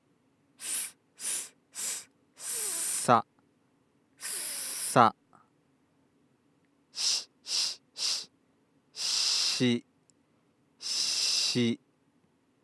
歯擦音で舌の使い方を観察してみる
音量注意！
歯擦音というのは上記の音源の最初に鳴らしている歯と歯の間を息が通過する際に鳴る音のことです。
lateral-lisp-02.mp3